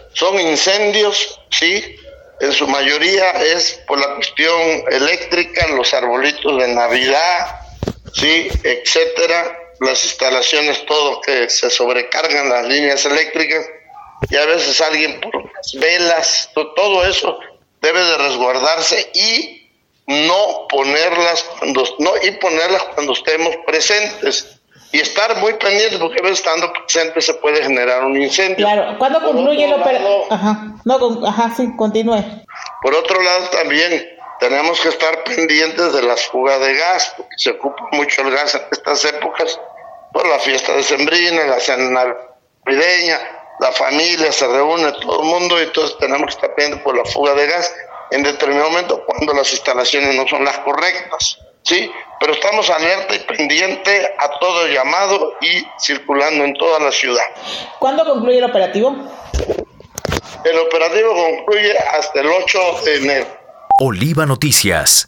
En entrevista enfatizó que muchos productos de baja calidad no soportan el voltaje adecuado, lo que puede derivar en cortocircuitos e incluso incendios.